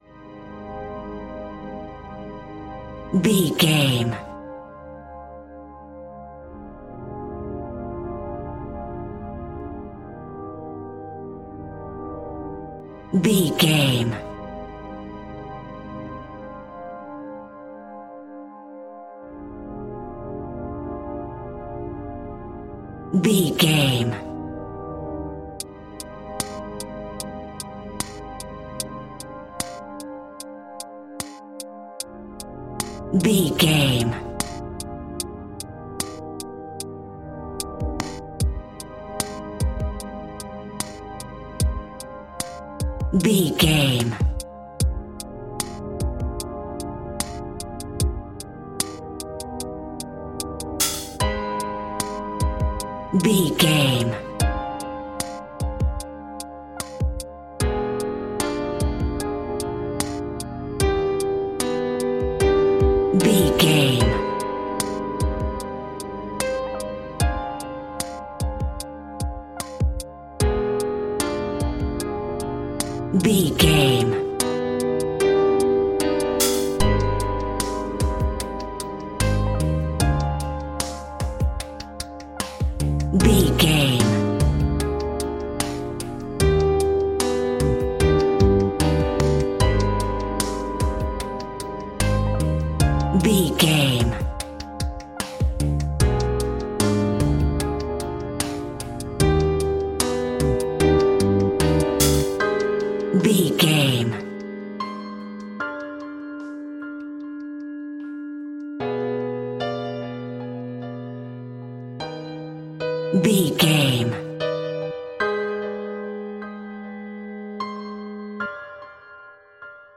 Aeolian/Minor
Slow
scary
tension
ominous
dark
eerie
synthesiser
drums
drum machine
Horror synth
Horror Ambience